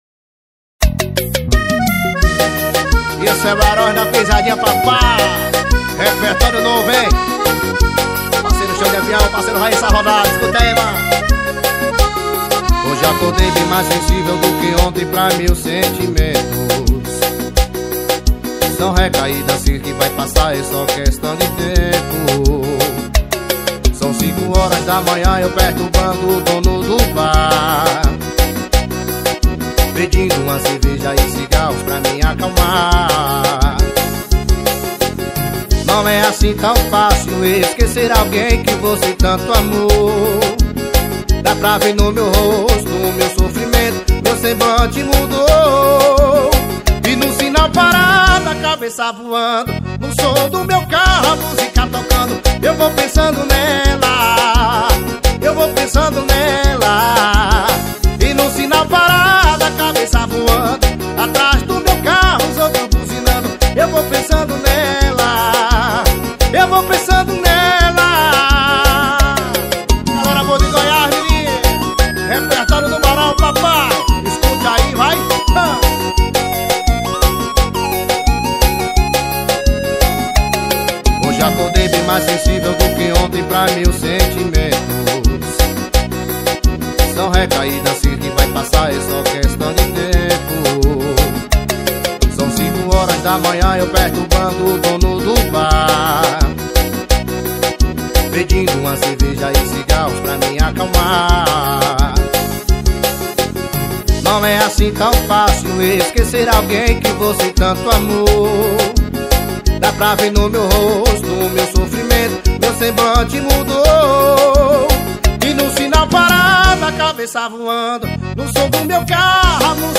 2024-10-09 13:40:11 Gênero: Forró Views